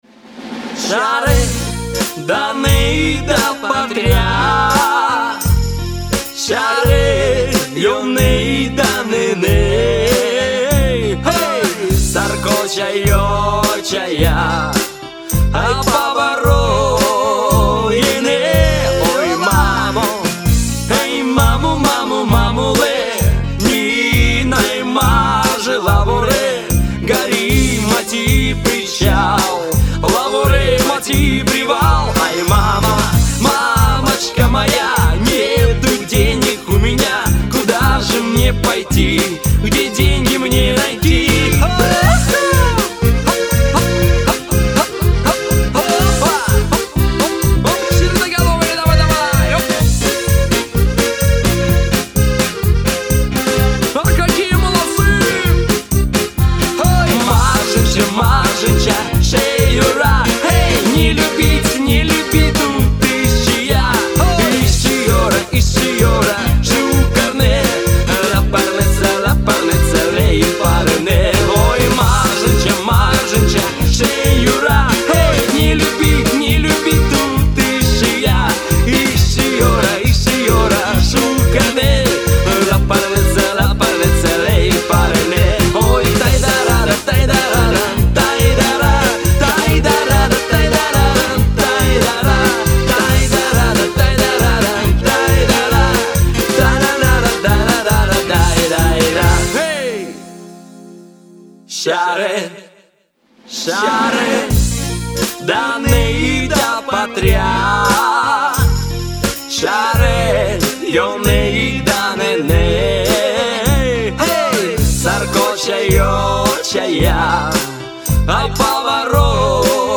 ресторанной группы 90-х из Борисоглебска